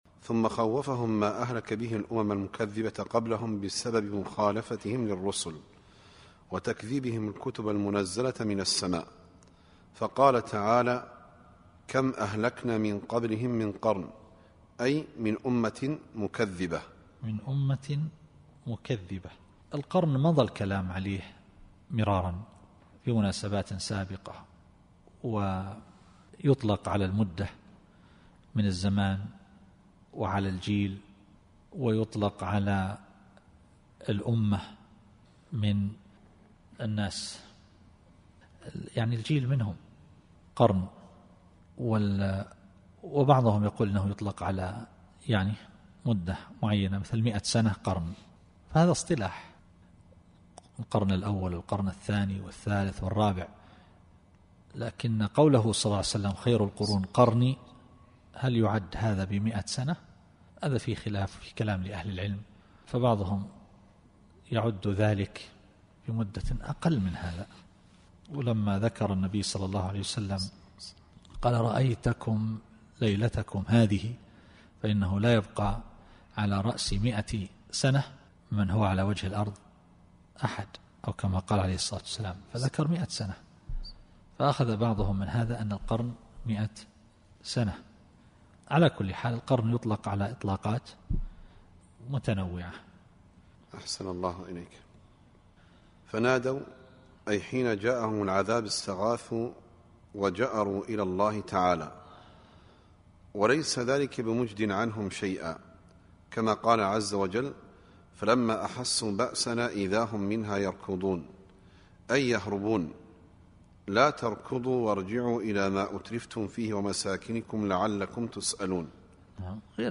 التفسير الصوتي [ص / 3]